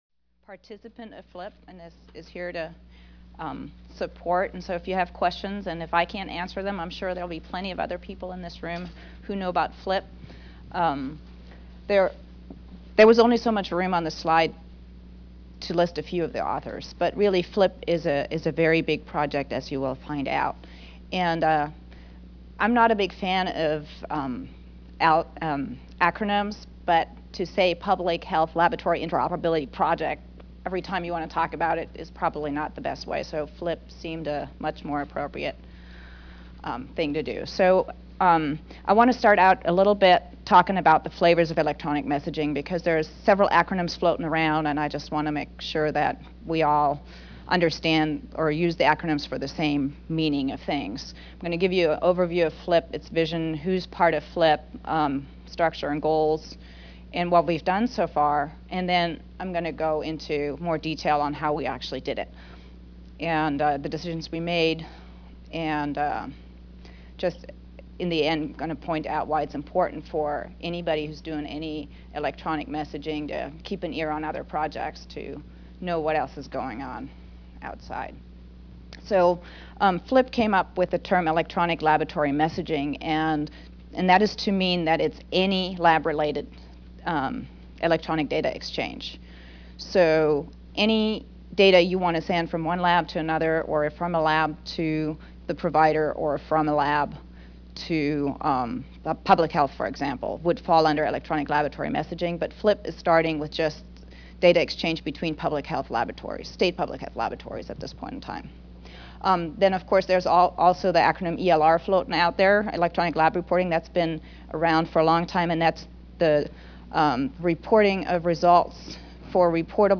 6th Annual Public Health Information Network Conference: Laboratory Messaging - Preventing Collisions At The Crossroad
Recorded presentation